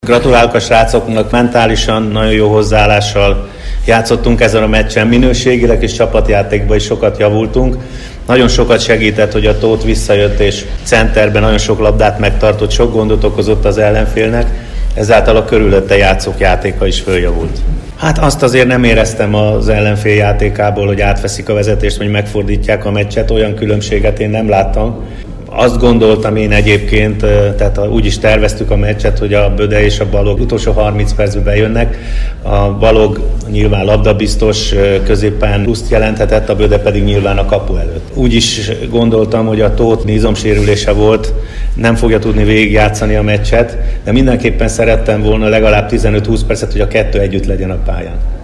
Bognár György vezetőedző a mérkőzést követő sajtótájékoztatón így értékelt.